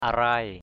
/a-raɪ/ 1. (d.) lúa rài = graines abandonnées qui germent après la moisson. abandoned germinating seeds after harvest. padai arai p=d a=r lúa rài. 2.